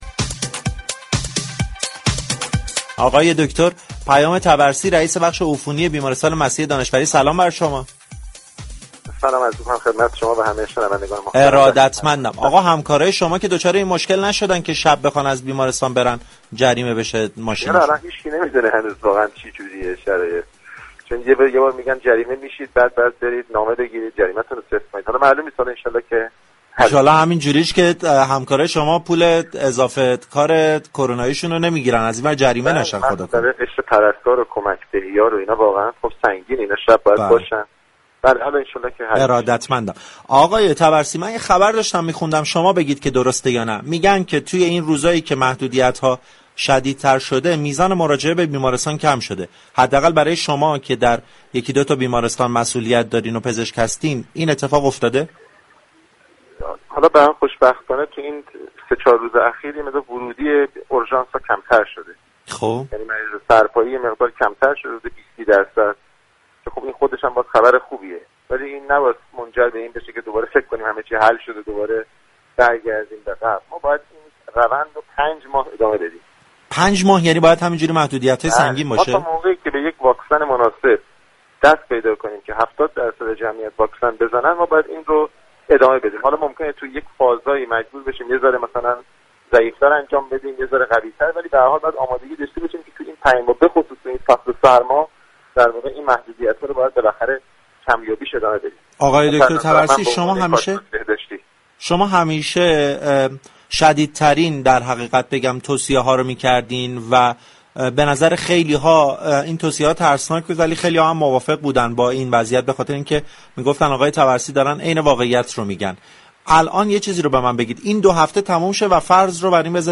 در گفتگو با برنامه تهران كلینیك